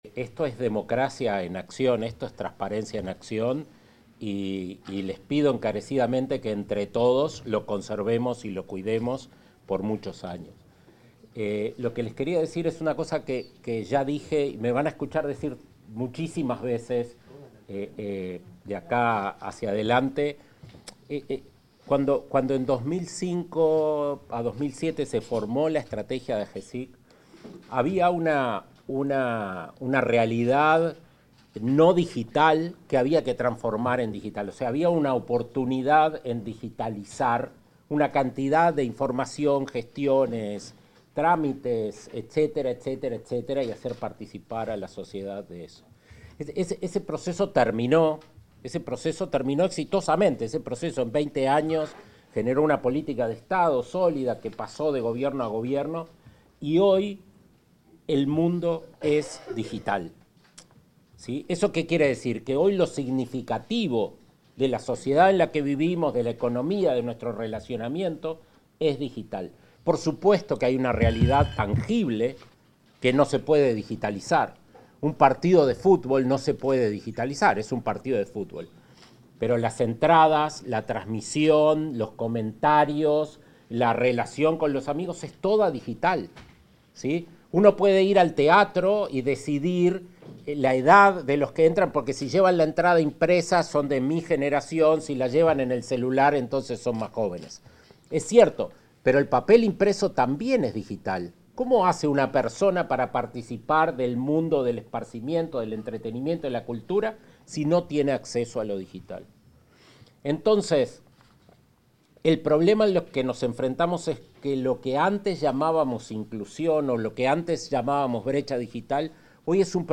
Palabras del director ejecutivo de Agesic, Daniel Mordecki